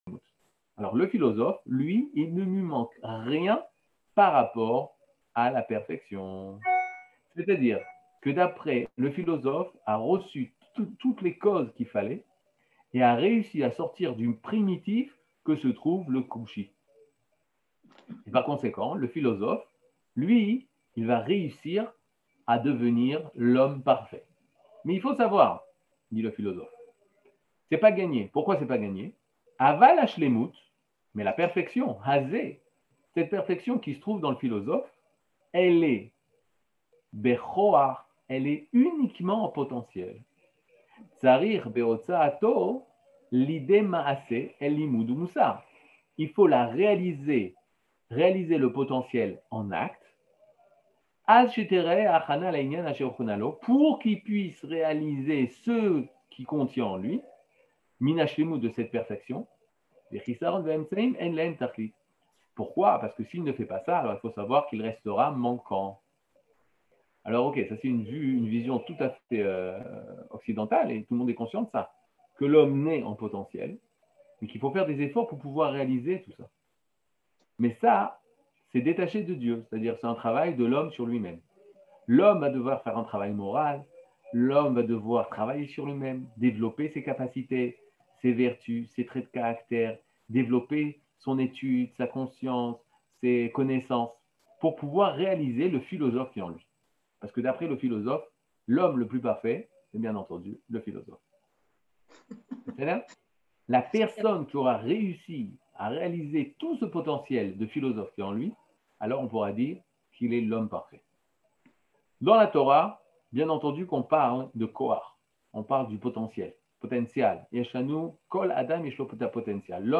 Catégorie Le livre du Kuzari partie 5 00:36:55 Le livre du Kuzari partie 5 cours du 16 mai 2022 36MIN Télécharger AUDIO MP3 (33.8 Mo) Télécharger VIDEO MP4 (72.71 Mo) TAGS : Mini-cours Voir aussi ?